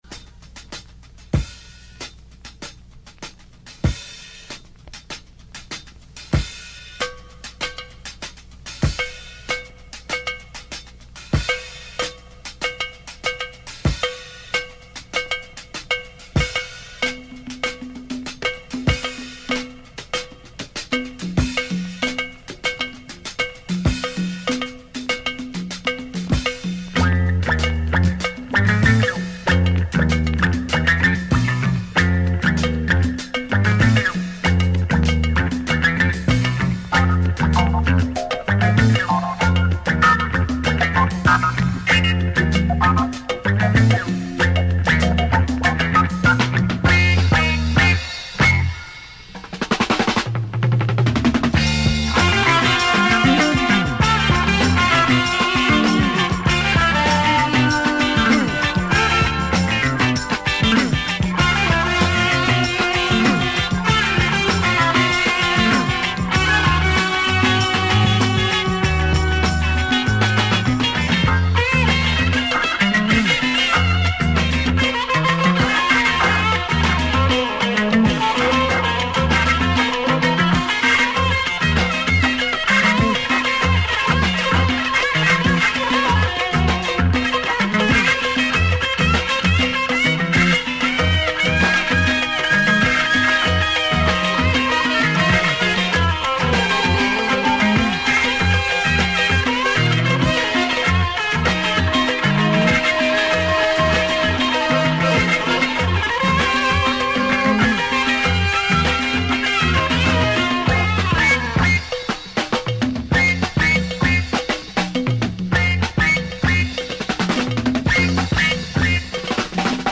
Wow, what a funk storm !